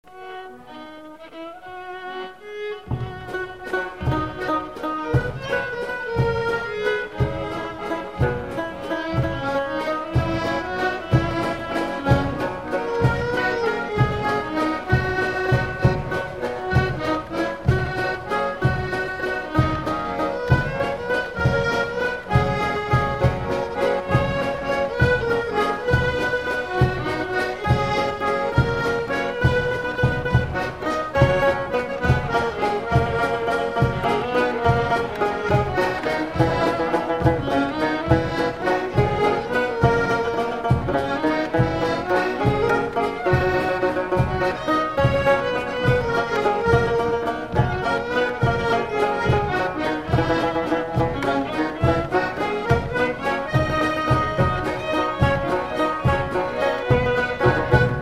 Résumé Instrumental
danse : valse
Pièce musicale inédite